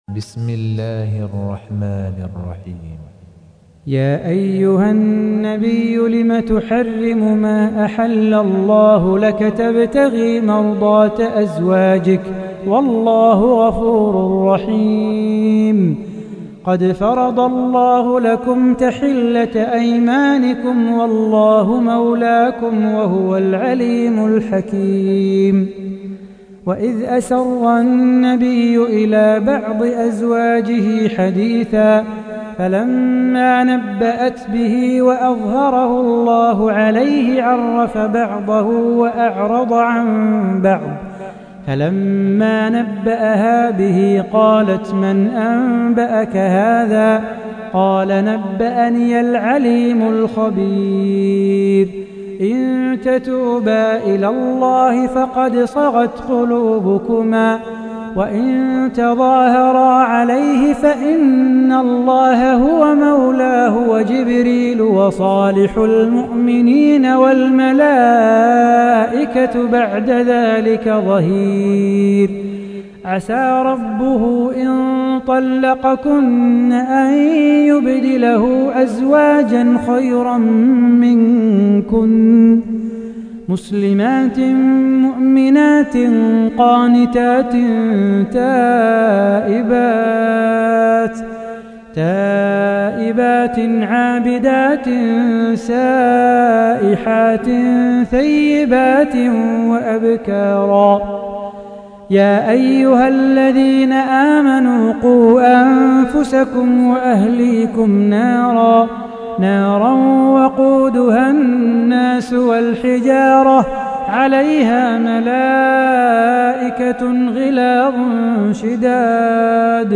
تحميل : 66. سورة التحريم / القارئ صلاح بو خاطر / القرآن الكريم / موقع يا حسين